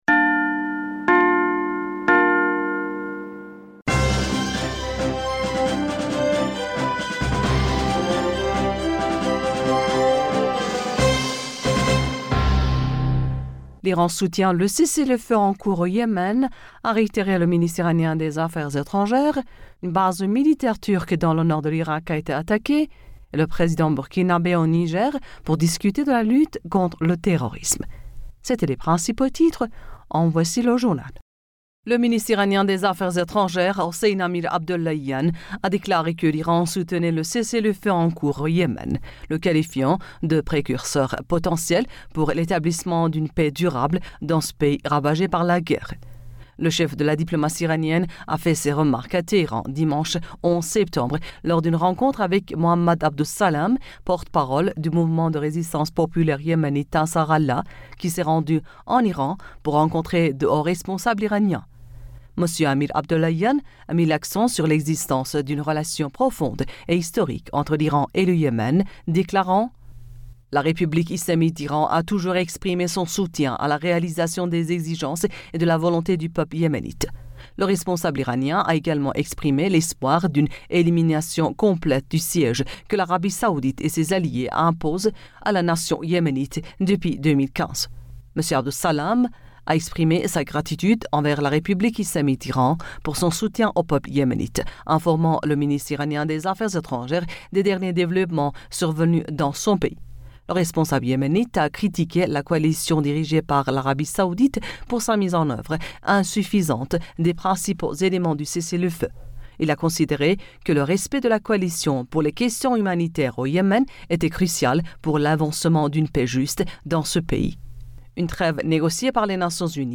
Bulletin d'information Du 12 Septembre